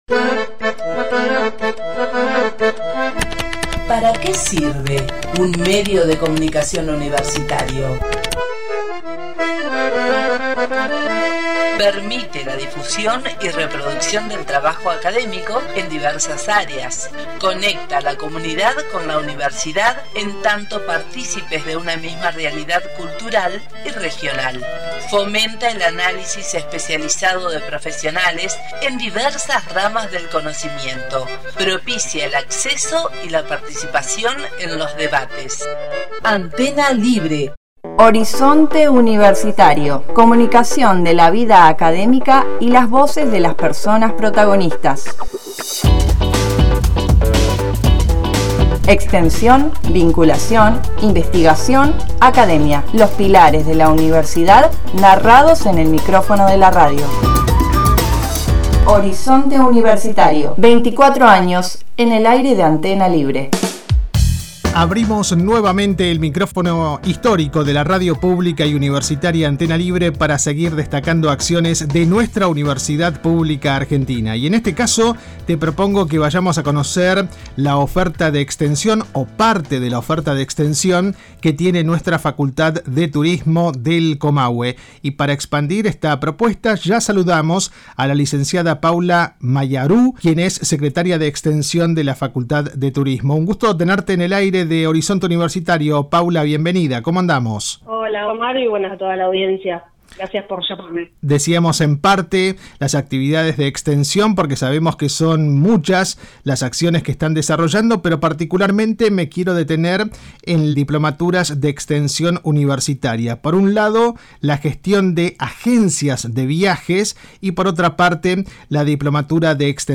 Al micrófono